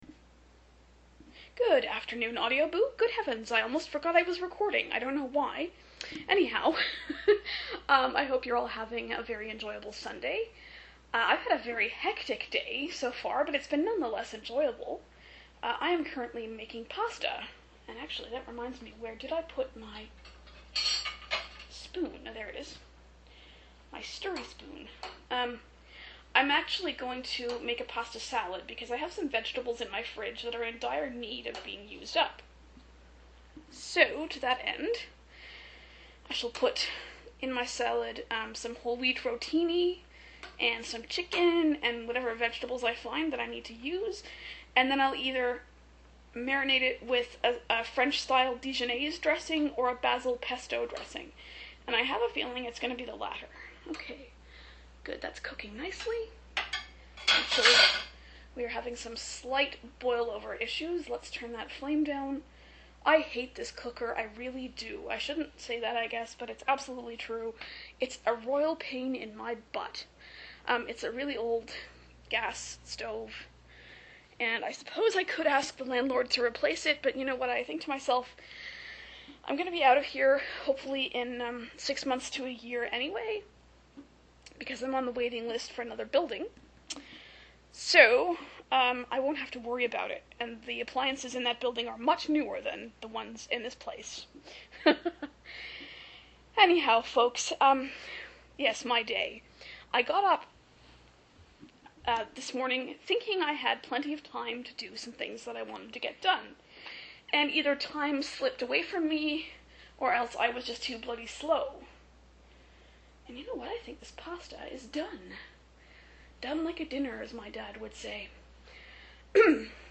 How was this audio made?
Join me for some chit-chat in my kitchen as I prepare a pasta salad. I actually decided to put tuna in it rather than chicken.